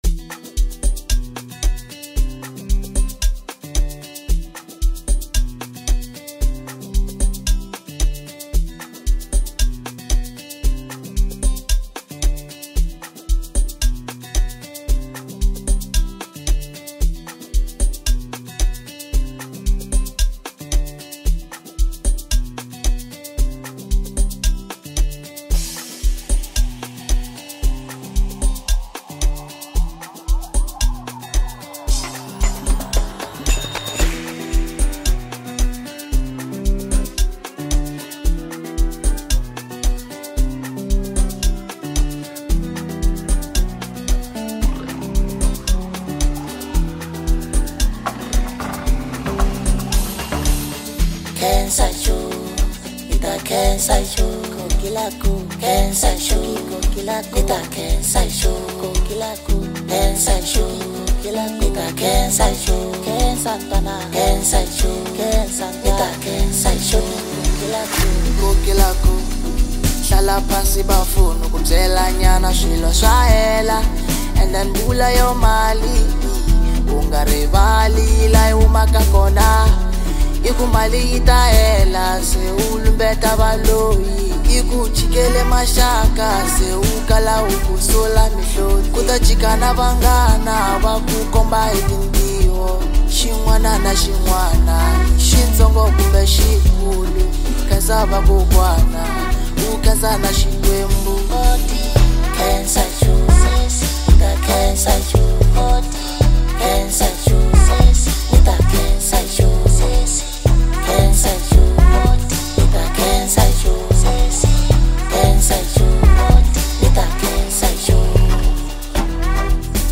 heartfelt and melodious
soulful and uplifting